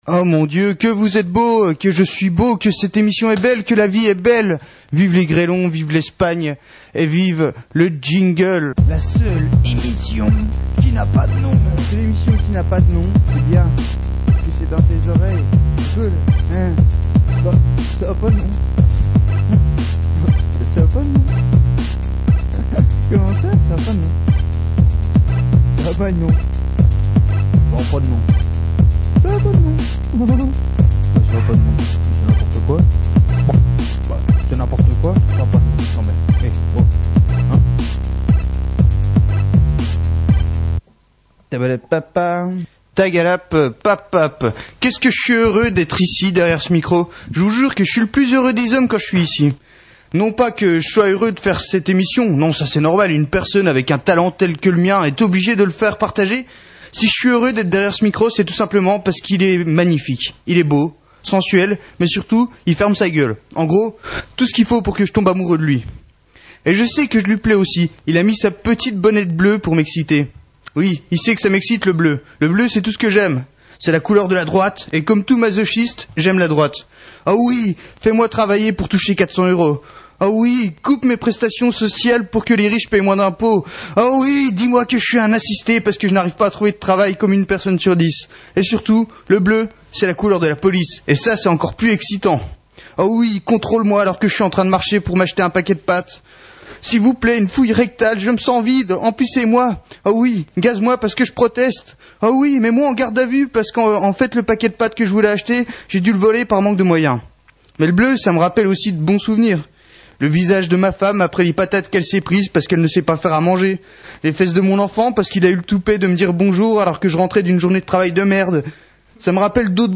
Interview Partie 1: Présentation de Prems